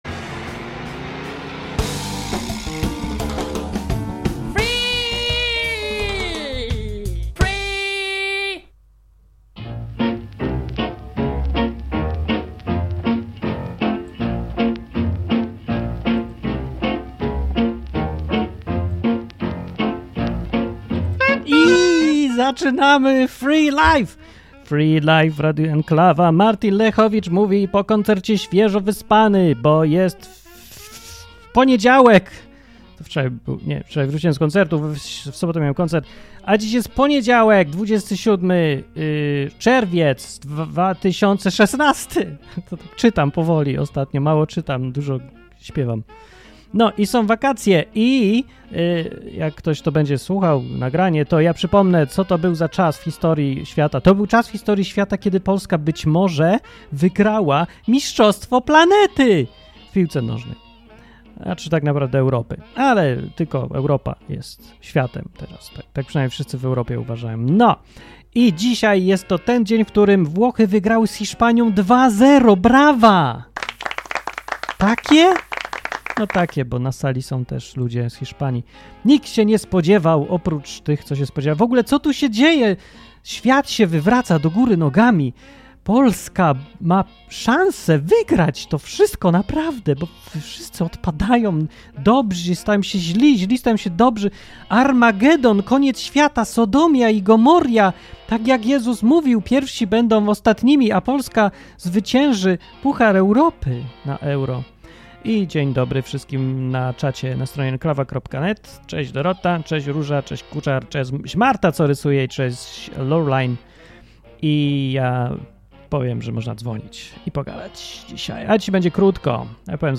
Program dla wszystkich, którzy lubią luźne, dzikie, improwizowane audycje na żywo.